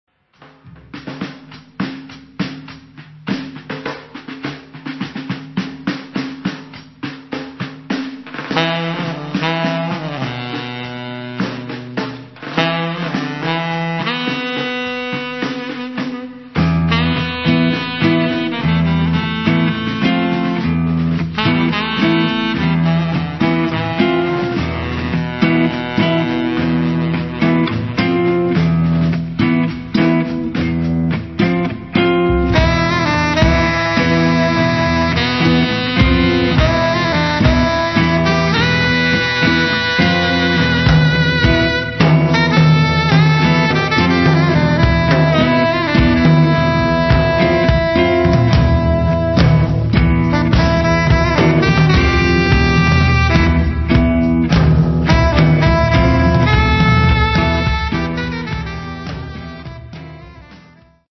spremljevalni vokali
saksofonom